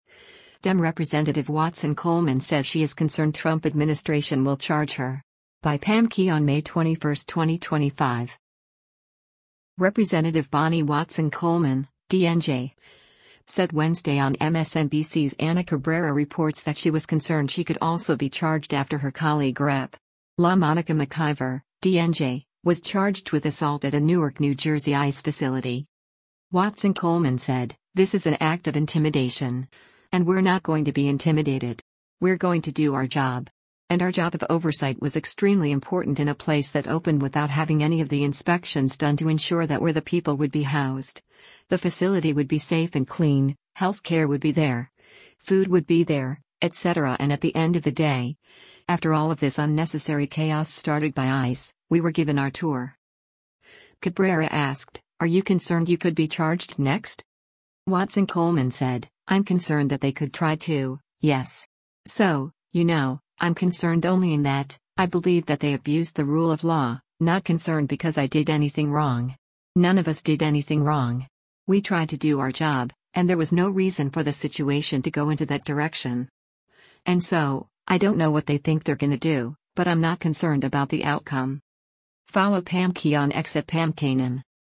Representative Bonnie Watson Coleman (D-NJ) said Wednesday on MSNBC’s “Ana Cabrera Reports” that she was “concerned” she could also be charged after her colleague Rep. LaMonica McIver (D-NJ) was charged with assault at a Newark, NJ ICE facility.